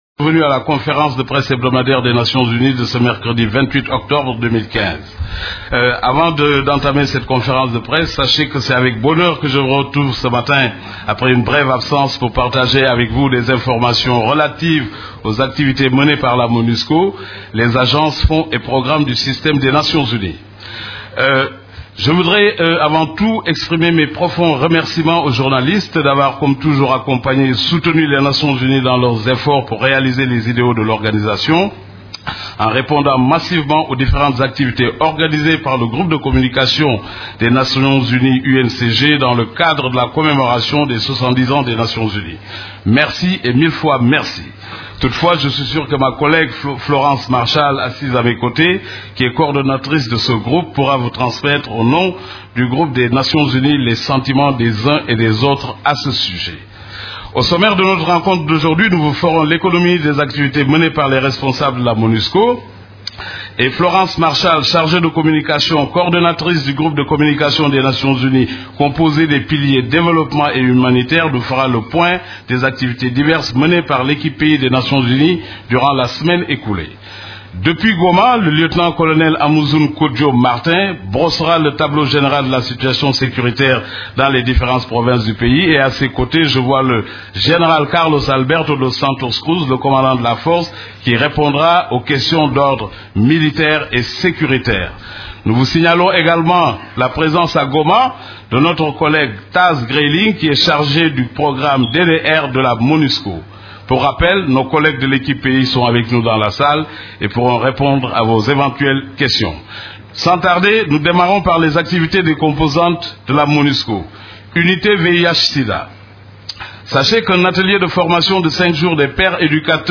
Conférence de presse du 28 octobre 2015
La conférence de presse hebdomadaire des Nations unies du mercredi 28 octobre a tourné autour des activités des composantes de la Monusco et celles des agences et programmes des Nations unies ainsi que de la situation militaire.
Vous pouvez écouter la première partie de la conférence de presse: